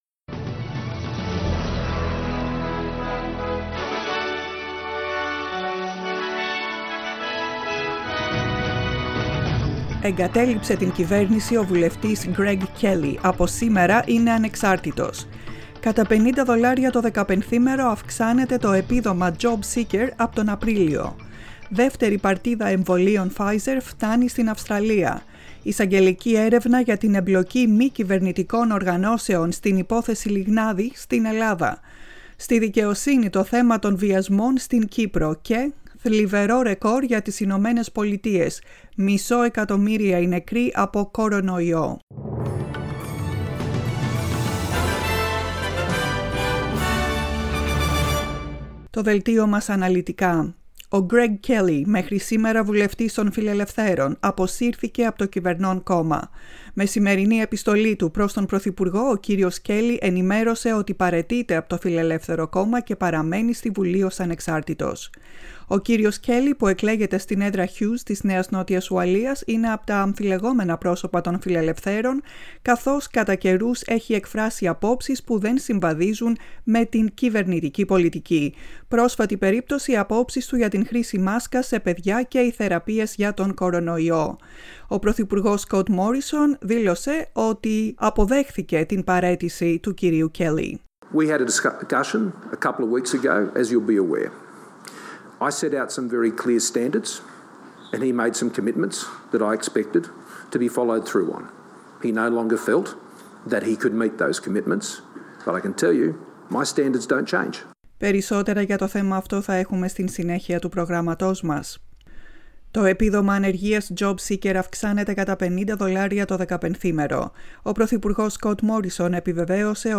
The main bulletin of the day